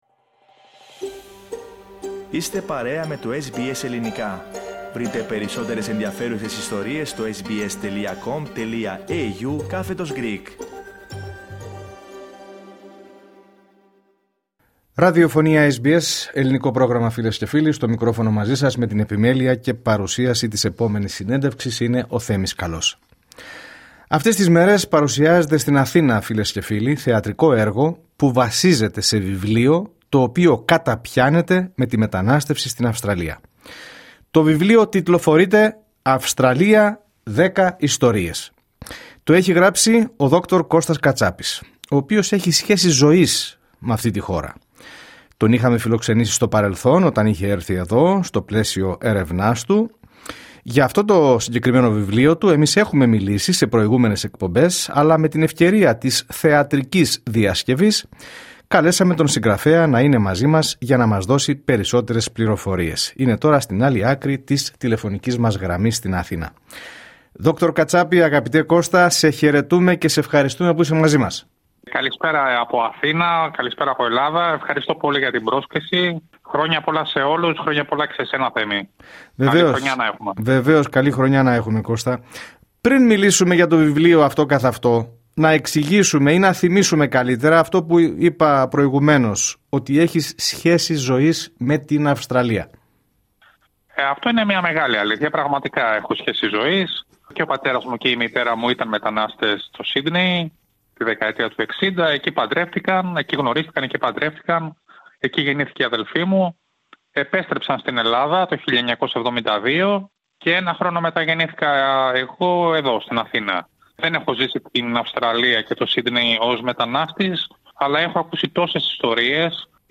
σε μια συζήτηση που κινήθηκε ανάμεσα στη μνήμη, την απώλεια, την αντοχή και την ταυτότητα του ανθρώπου της διασποράς.